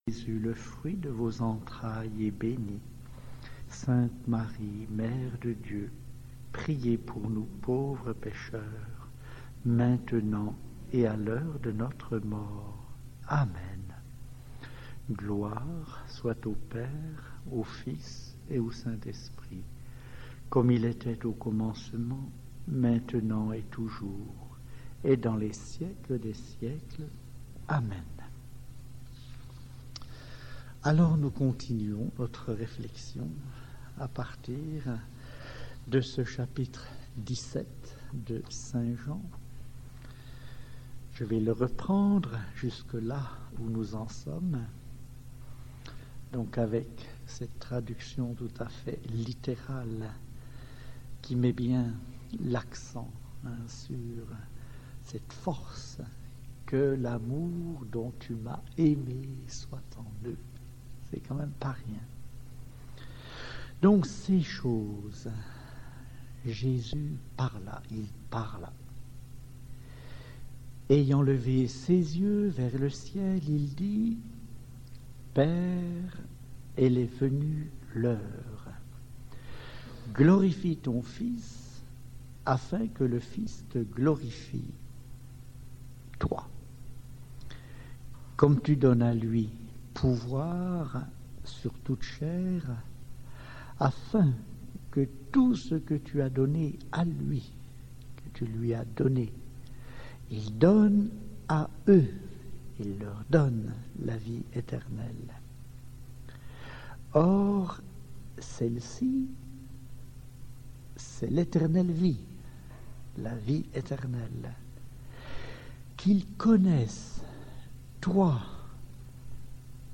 (Formation « Ecole de communion », mai 2008)